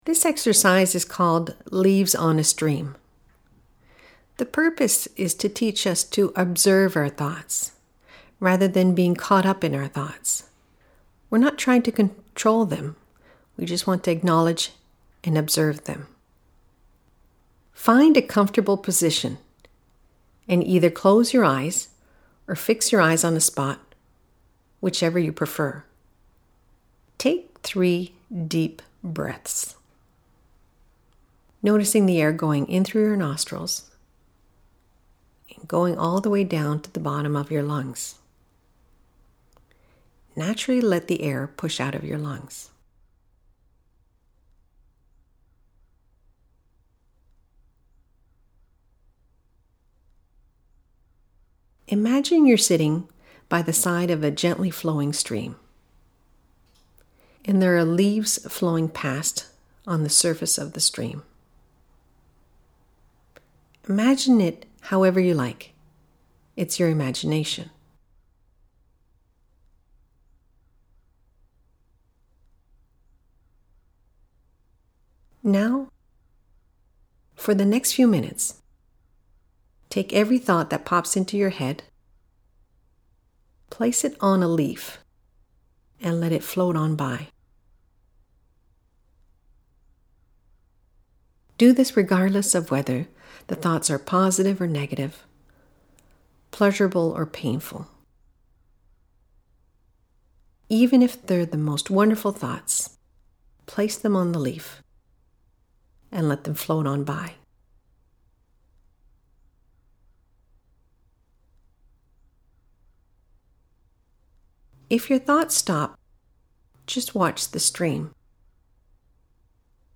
Our audio recordings are made at studio Neptunes Music .